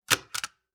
Part_Assembly_01.wav